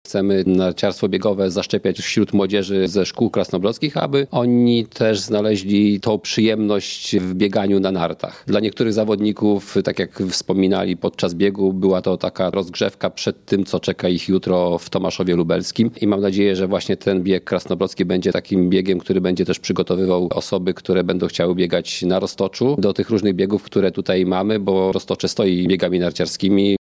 – Dopisali zarówno uczestnicy, jak również pogoda i atmosfera – mówi burmistrz Krasnobrodu, Kazimierz Misztal.